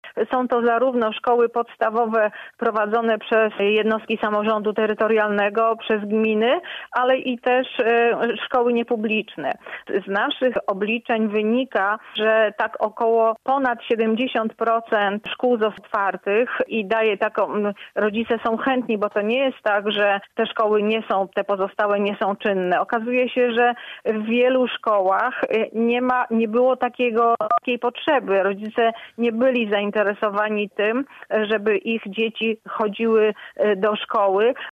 Mówi lubuski kurator oświaty, nasz poranny gość Ewa Rawa i dodaje, że większość przedszkoli w naszym województwie została już otwarta: